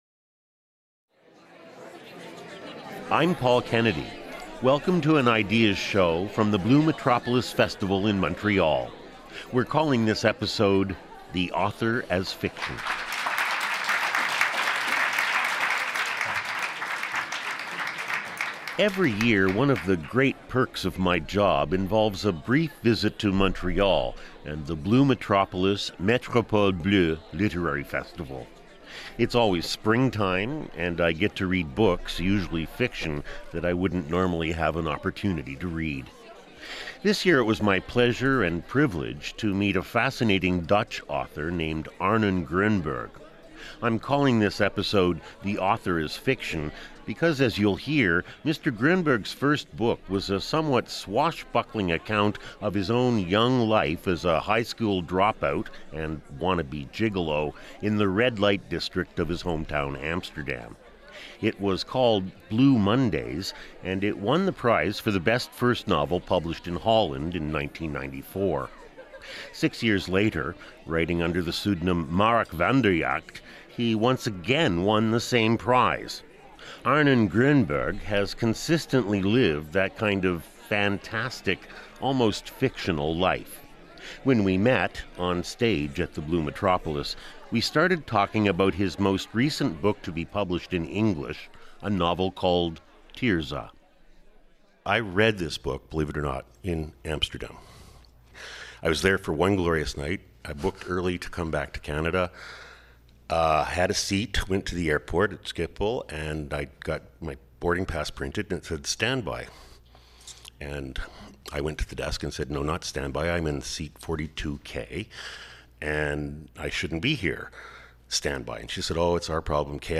On the 27th of April 2013 Arnon Grunberg was a guest at the Blue Metropolis festival in Montreal. He joined a discussion about absent mothers in literature and was interviewed about his novel Tirza.